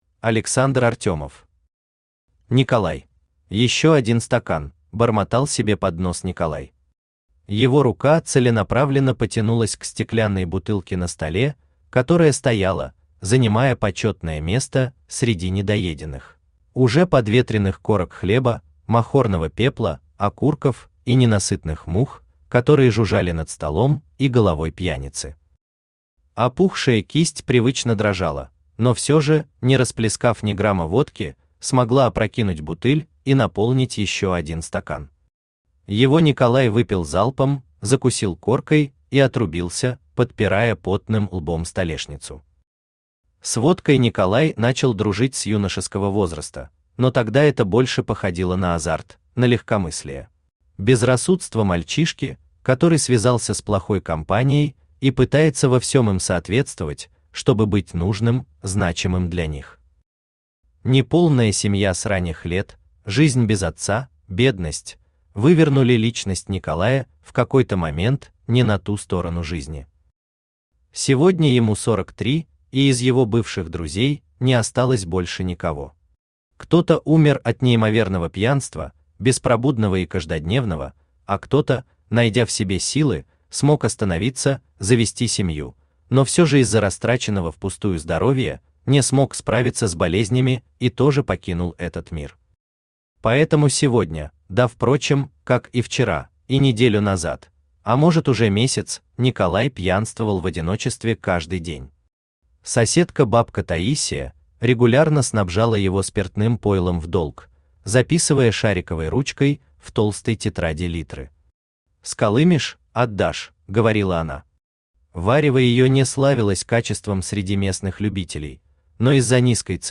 Аудиокнига Николай | Библиотека аудиокниг
Aудиокнига Николай Автор Александр Артемов Читает аудиокнигу Авточтец ЛитРес.